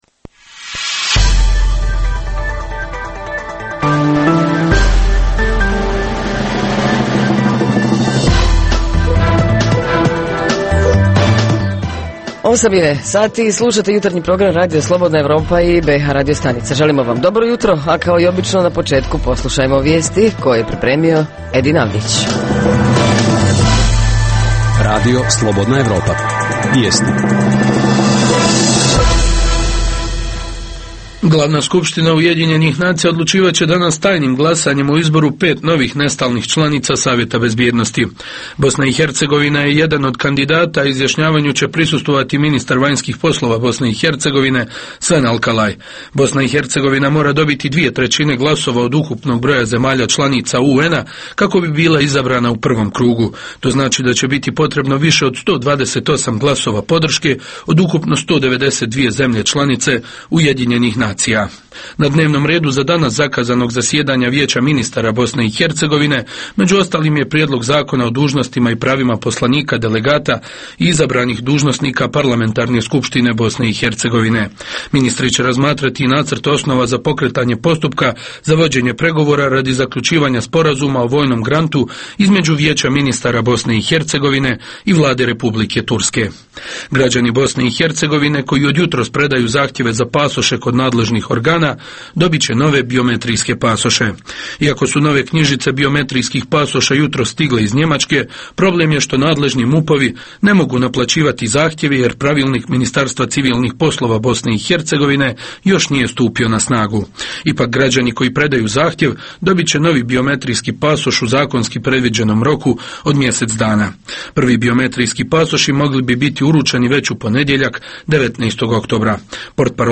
Tema jutra: Zima i komunalci – jesu li spremno dočekali prvi snijeg? Reporteri iz cijele BiH javljaju o najaktuelnijim događajima u njihovim sredinama.
Redovni sadržaji jutarnjeg programa za BiH su i vijesti i muzika.